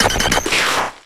DIGLETT.ogg